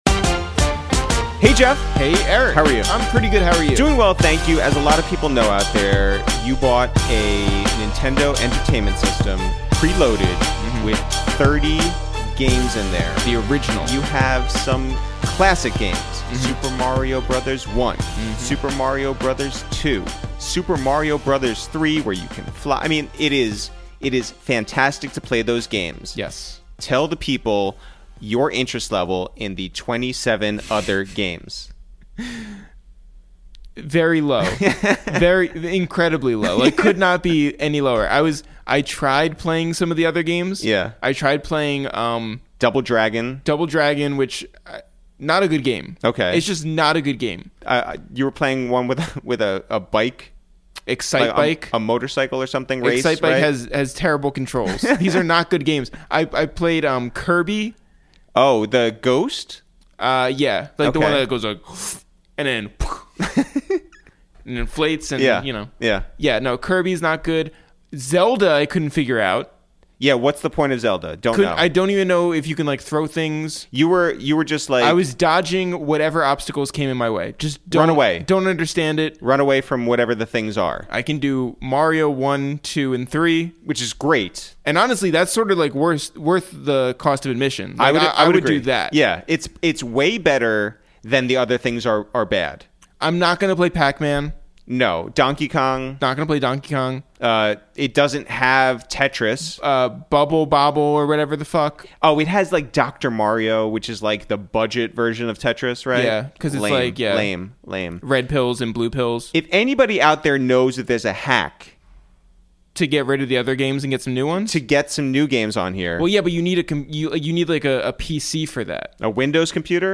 Today on Episode 11 of Quarantine Radio, we make calls from our Upper West Side apartment to check in on New Orleans' favorite son Curren$y